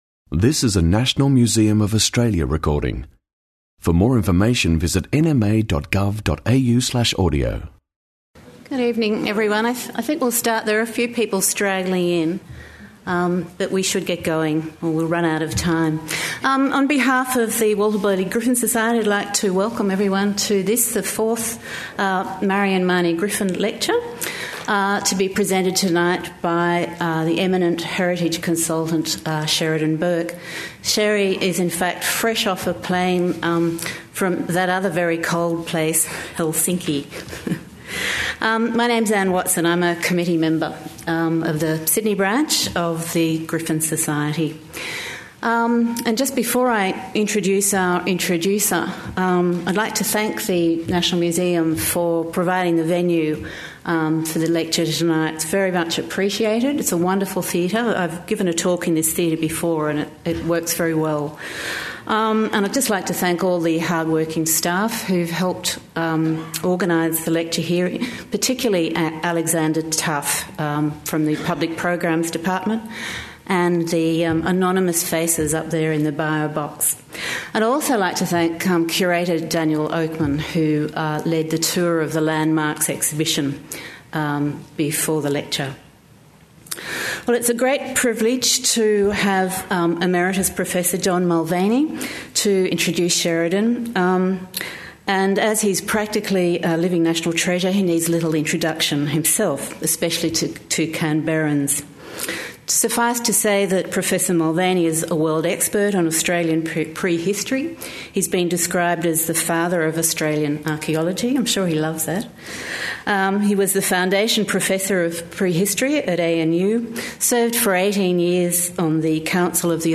Marion Mahony Griffin Annual Lecture 2012: presented in assocation with the Walter Burley Griffin Society Inc. | National Museum of Australia